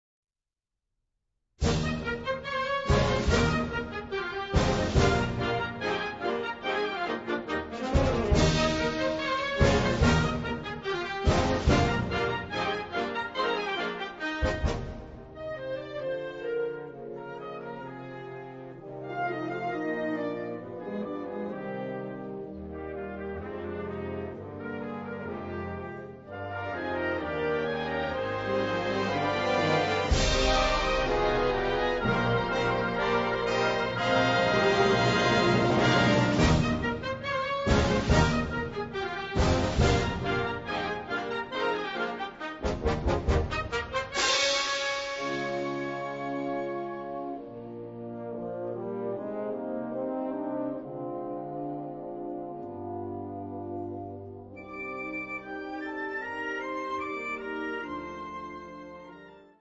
Gattung: Ouvertüre
4:10 Minuten Besetzung: Blasorchester Tonprobe